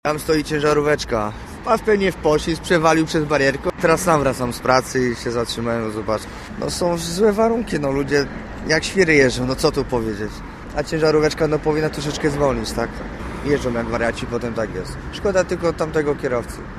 psz4or2dmfun634_z-miejsca-zdarzenia.mp3